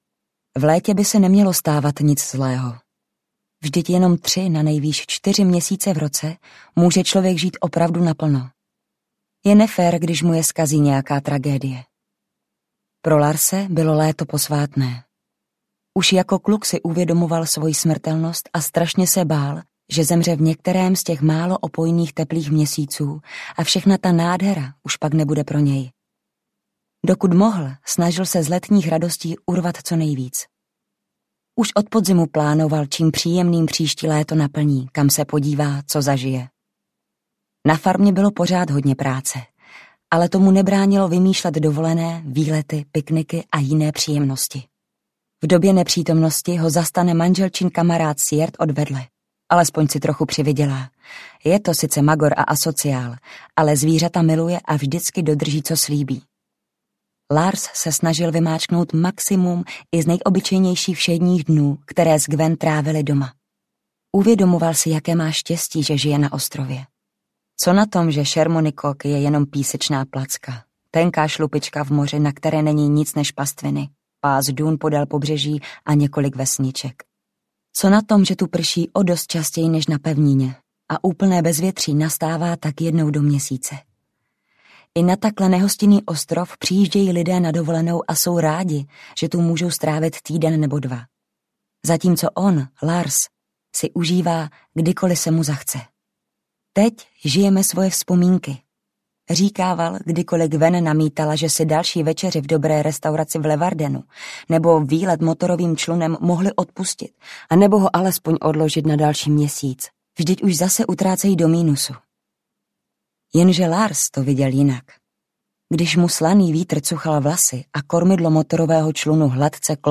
Ostrov šedých mnichů audiokniha
Ukázka z knihy